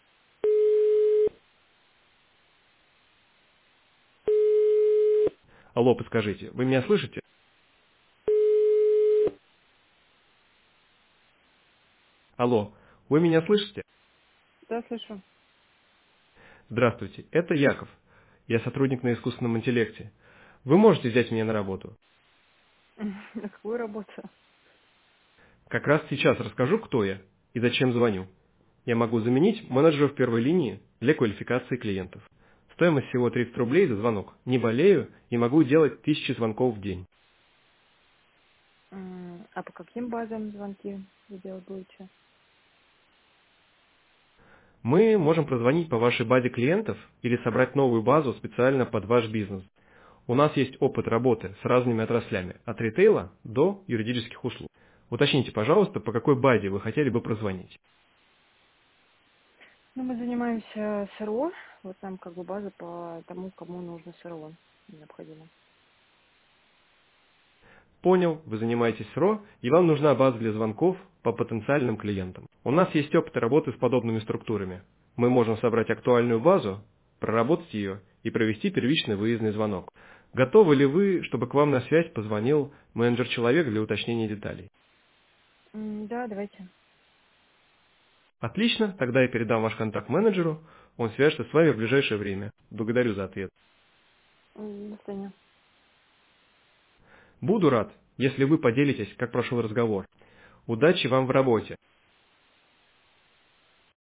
“AI-звонок”
• Очень реалистичный голос
голос Яков - продажа искусственного интеллекта